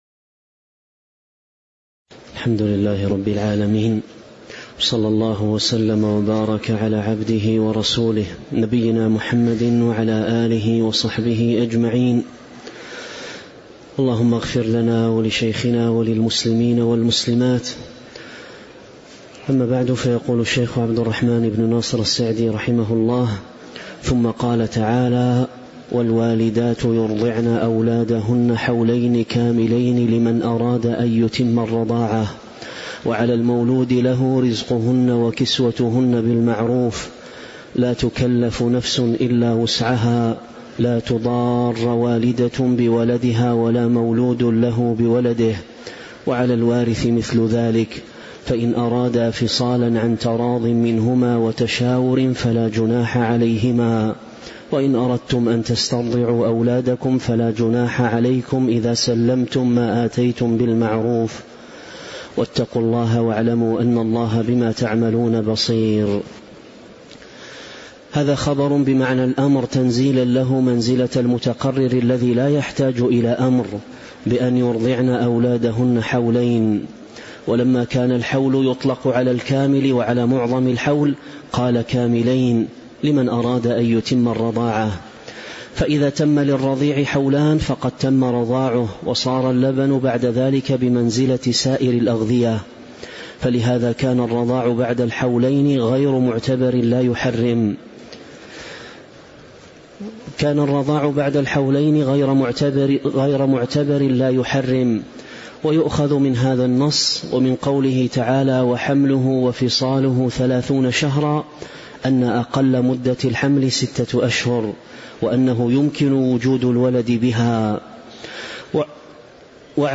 تاريخ النشر ١٩ شعبان ١٤٤٦ هـ المكان: المسجد النبوي الشيخ: فضيلة الشيخ عبد الرزاق بن عبد المحسن البدر فضيلة الشيخ عبد الرزاق بن عبد المحسن البدر تفسير سورة البقرة من آية 233 (097)